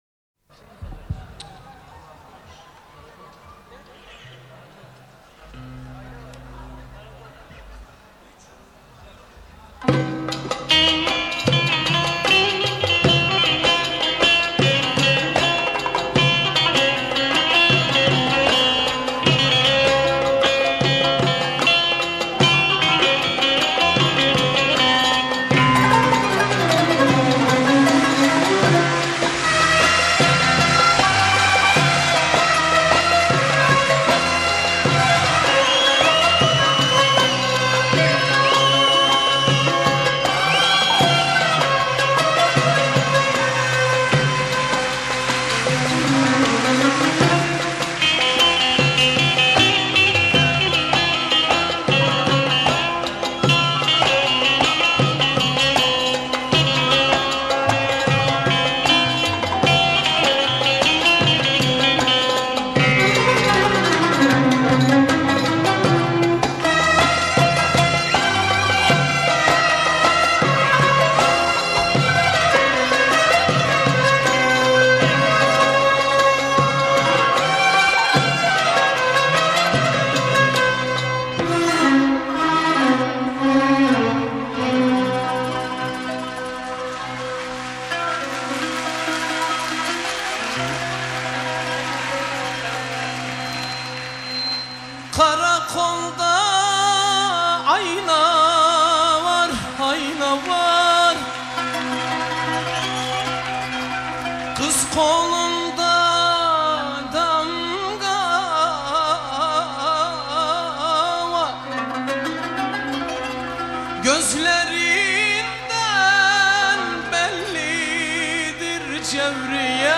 Arabesk, Turkish Pop